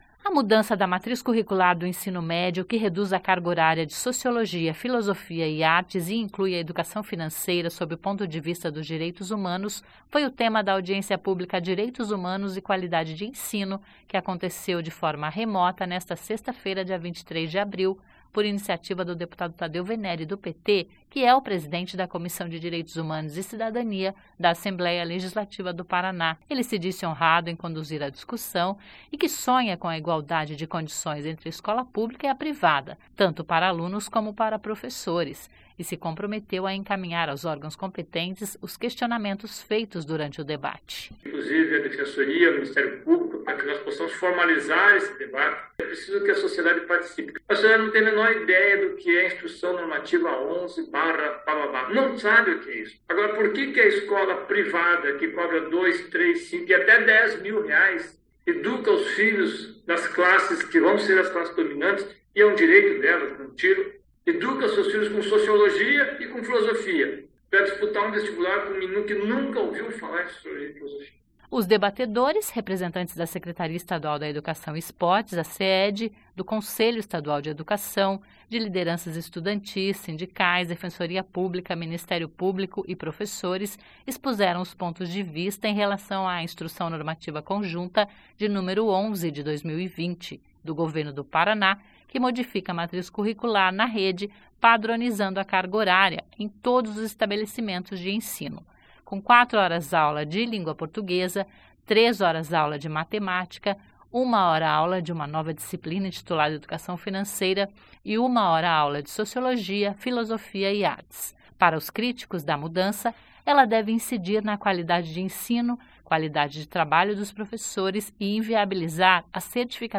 Audiência pública debate a matriz curricular no Ensino Médio
A mudança da matriz curricular do Ensino Médio que reduz a carga horária de sociologia, filosofia e artes e inclui a educação financeira sob o ponto de vista dos direitos humanos foi o tema da audiência pública Direitos Humanos e Qualidade de Ensino, que aconteceu de forma remota nesta sexta-feira...